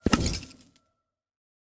Minecraft Version Minecraft Version latest Latest Release | Latest Snapshot latest / assets / minecraft / sounds / block / vault / eject3.ogg Compare With Compare With Latest Release | Latest Snapshot
eject3.ogg